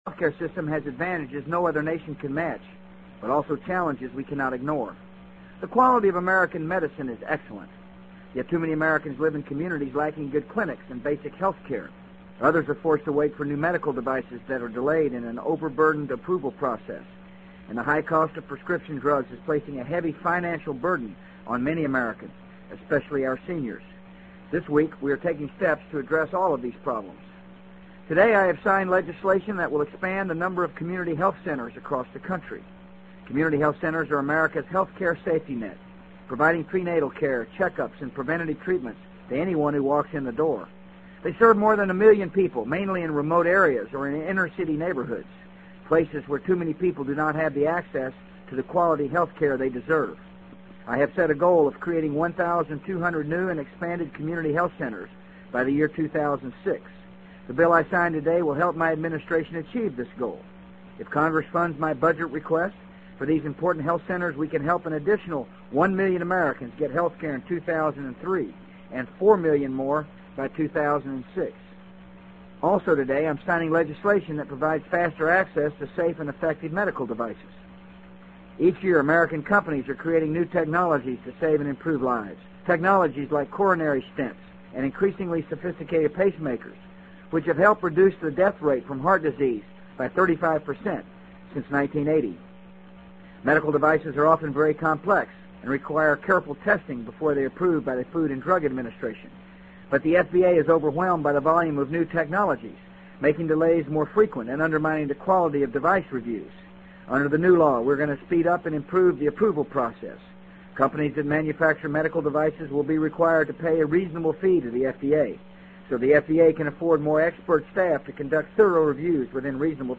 【美国总统George W. Bush电台演讲】2002-10-26 听力文件下载—在线英语听力室